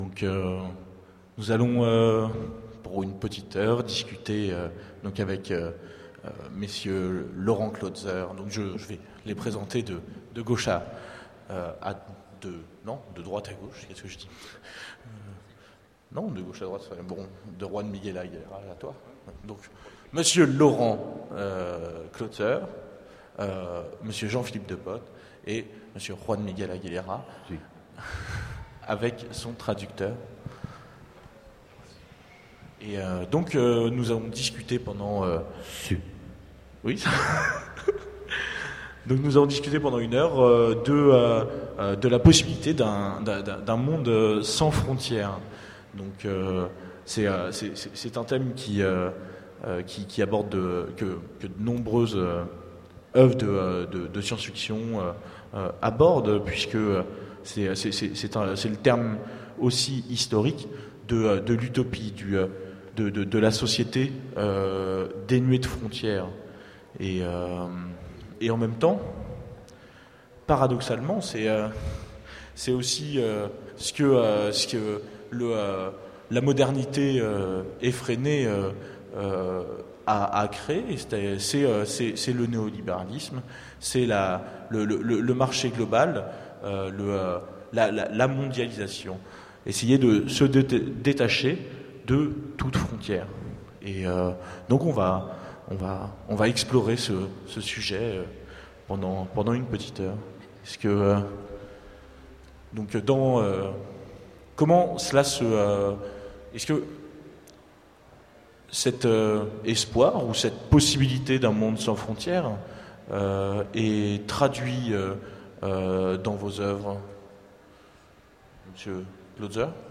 Utopiales 2010 : Conférence, Peut-on vivre sans frontière ?